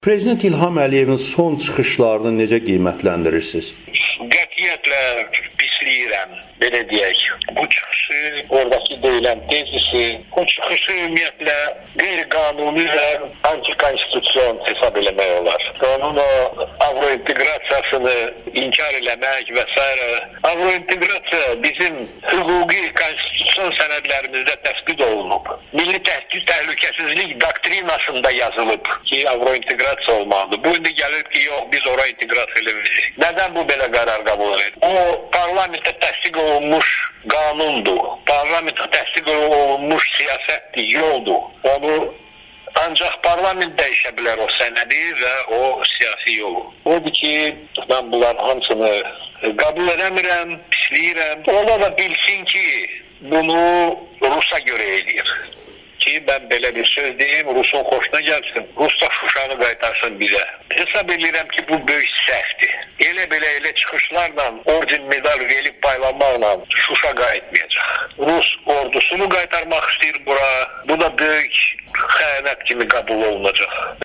Politoloq, Azərbaycanın Rusiyadakı keçmiş səfiri Hikmət Hacızadə Amerikanın Səsinə müsahibəsində prezident İlham Əliyevin son çıxışlarını `pislədiyini` deyib.